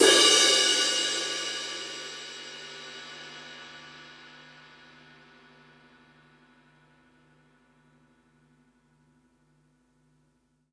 Lightningbeam / src / assets / instruments / drums / drum-kit / samples / crash2_ff.mp3
crash2_ff.mp3